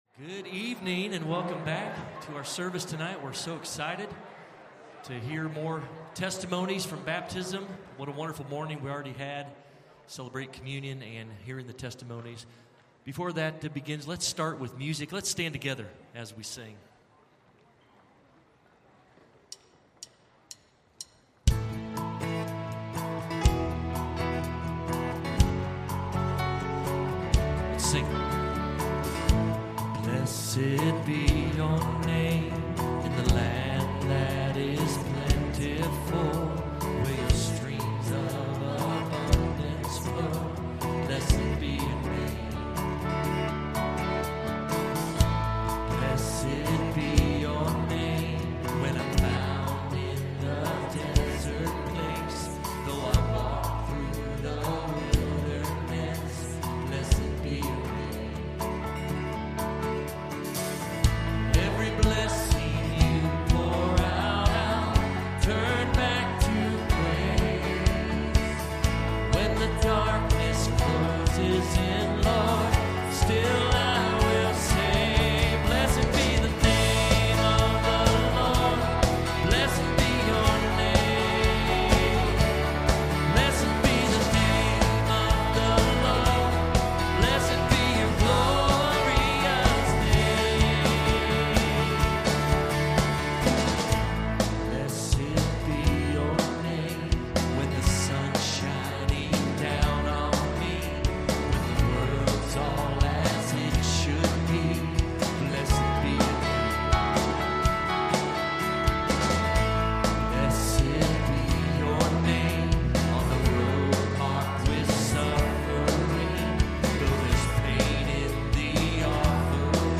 Evening Baptism Service